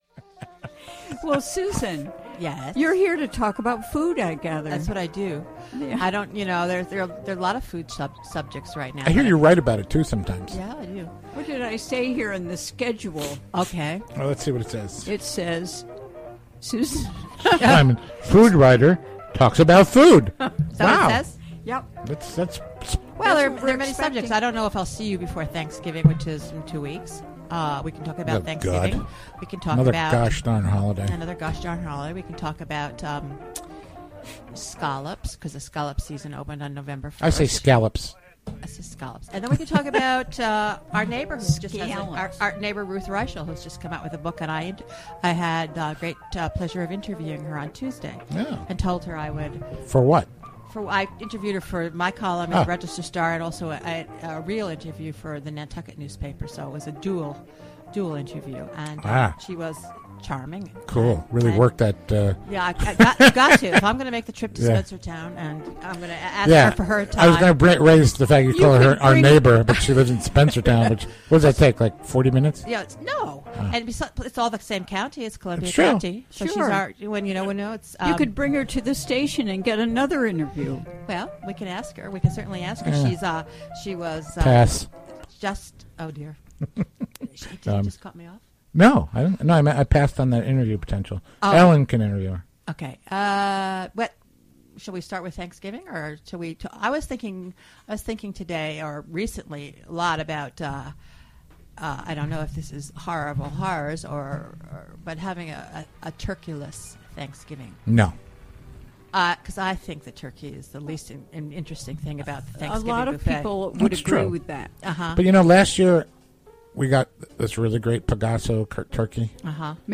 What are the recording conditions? Recorded during the WGXC Afternoon Show, Thu., Nov. 12, 2015.